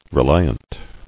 [re·li·ant]